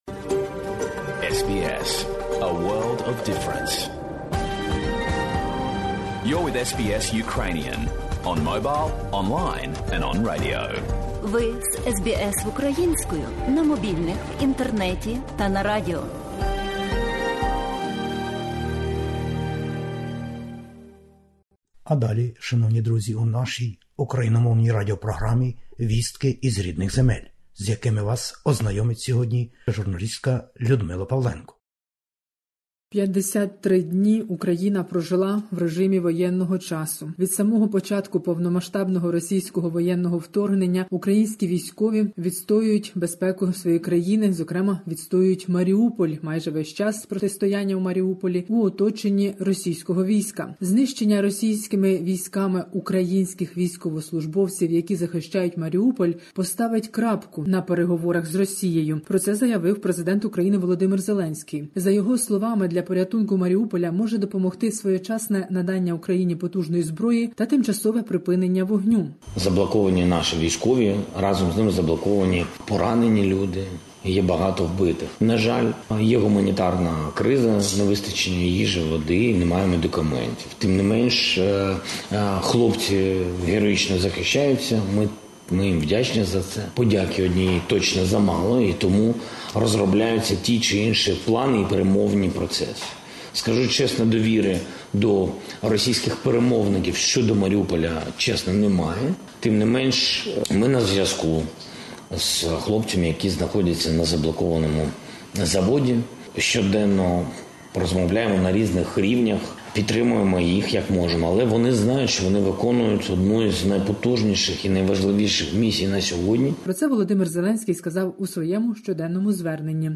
Добірка новин із України спеціально для SBS Ukrainian.